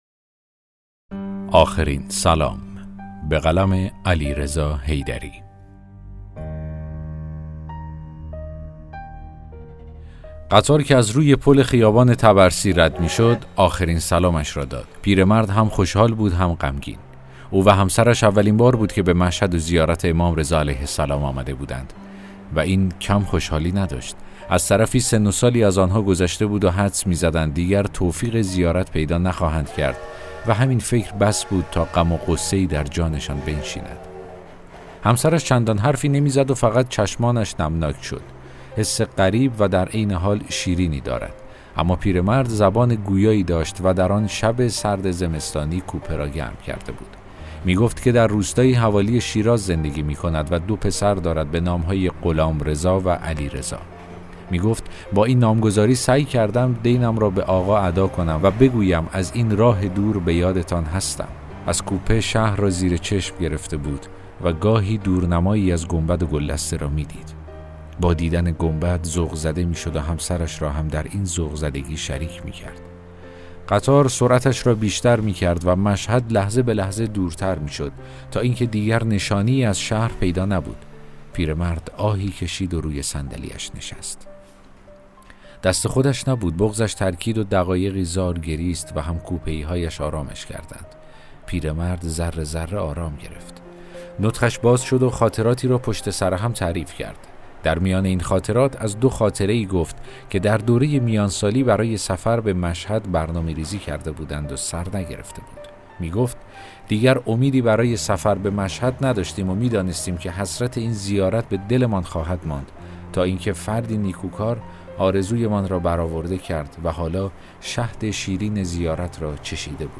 داستان صوتی: آخرین سلام